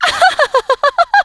piper_ulti_01.wav